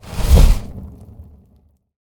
meleeattack-swoosh-magicaleffect-group01-fire-02.ogg